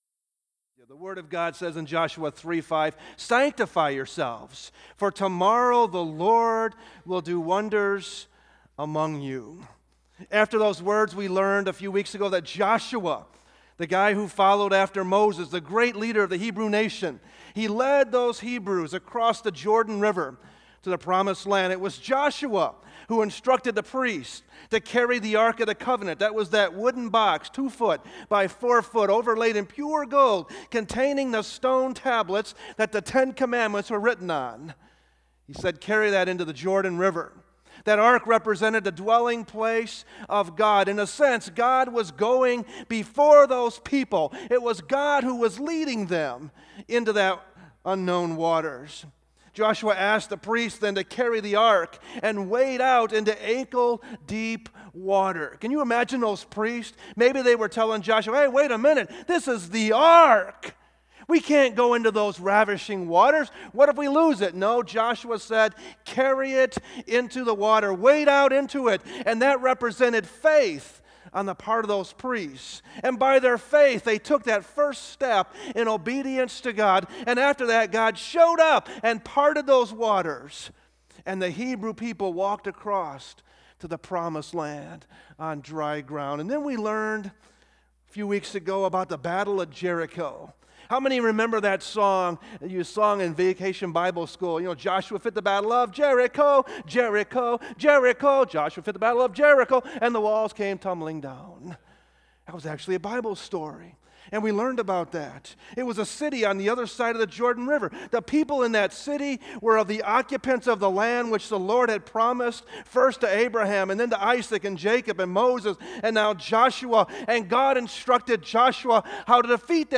sermons preached at Grace Baptist Church in Portage, IN